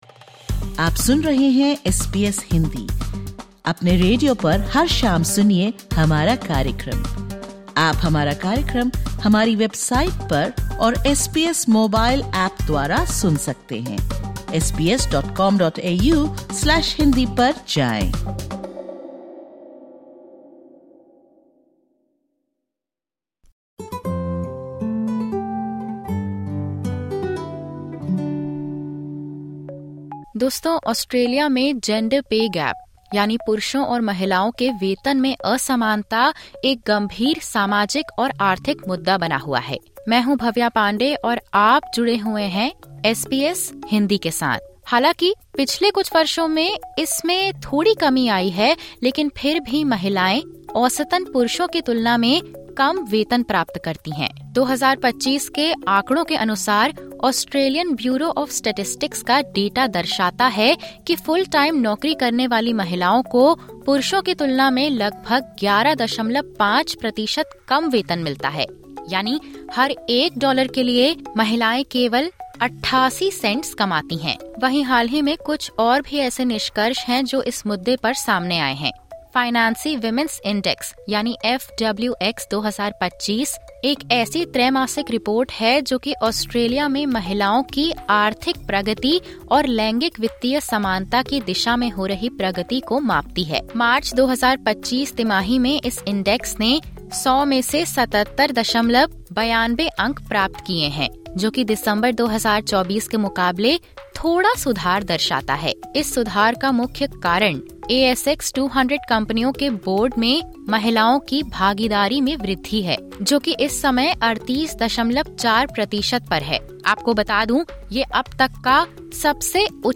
FWX_JUNE_QTR25_Sept8.pdf ( Disclaimer: The views/opinions expressed in this interview are the personal views of the individual.